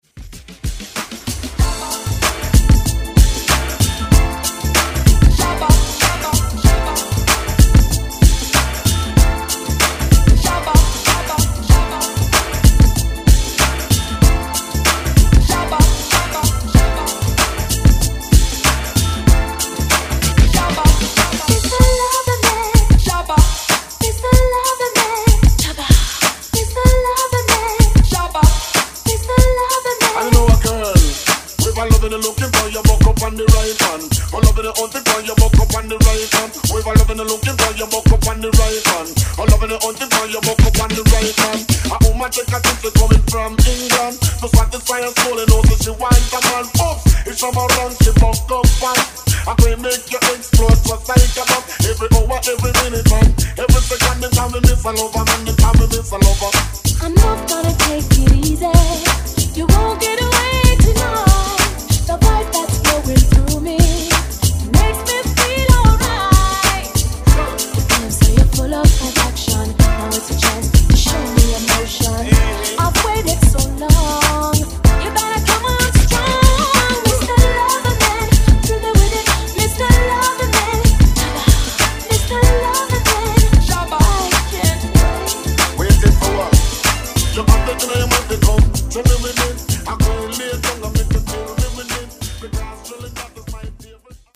Genres: 2000's , 90's , RE-DRUM
Clean BPM: 95 Time